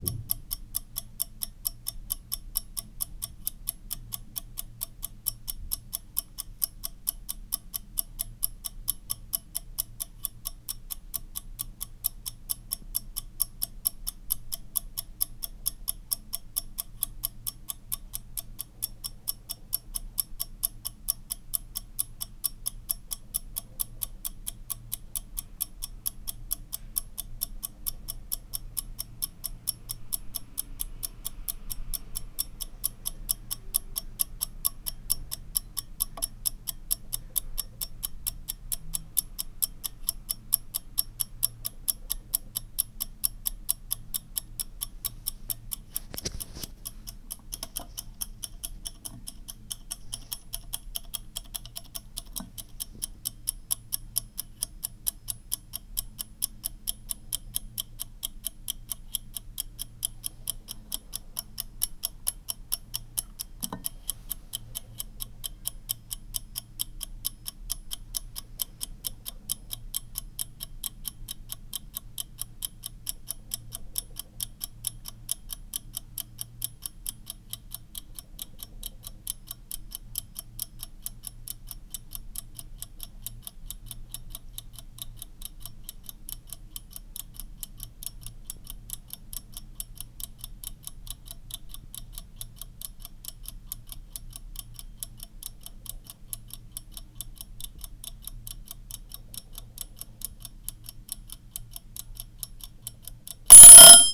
Kitchen timer and alarm bell
alarm bell chime clock ding kitchen ring ringing sound effect free sound royalty free Sound Effects